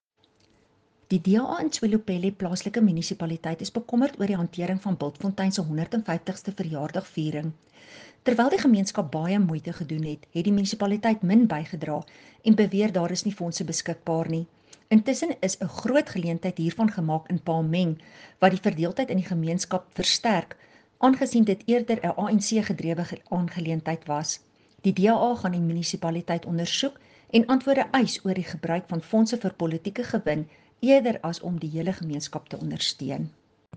Afrikaans soundbites by Cllr Estelle Pretorius and Sesotho by Jafta Mokoena MPL.